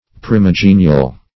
Search Result for " primigenial" : The Collaborative International Dictionary of English v.0.48: Primigenial \Pri`mi*ge"ni*al\, a. First born, or first of all; original; primary.